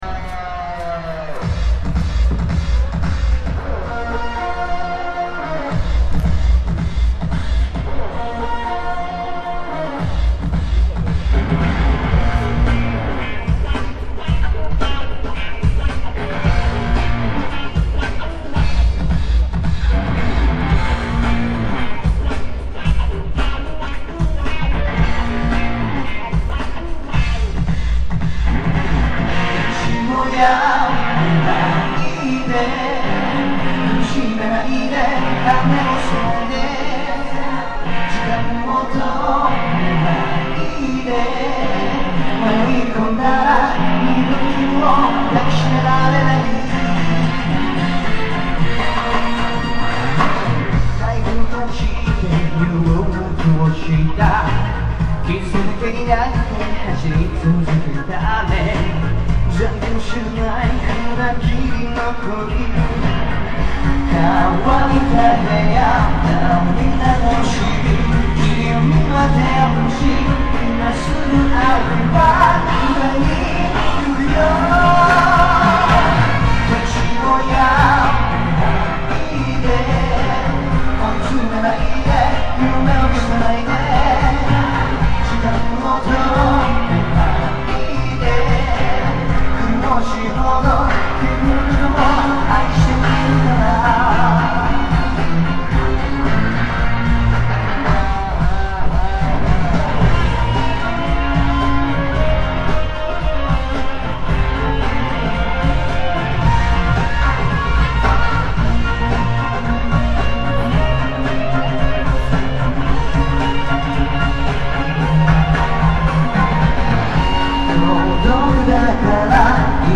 Conert repo